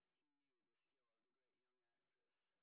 sp02_street_snr30.wav